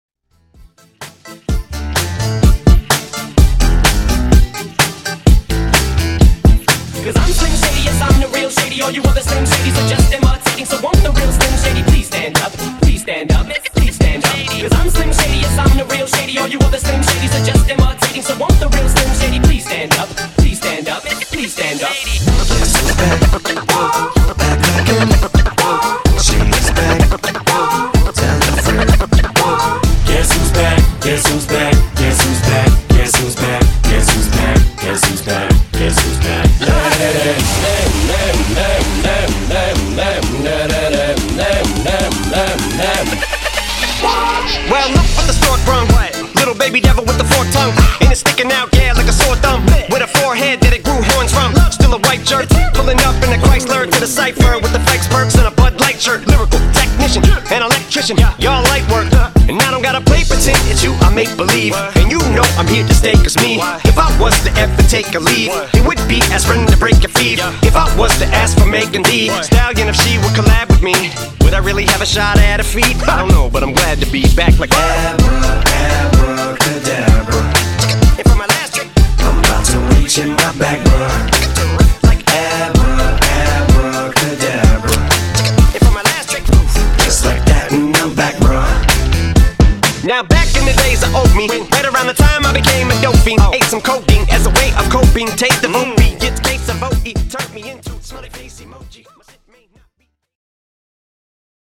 Genre: DANCE Version: Clean BPM: 120 Time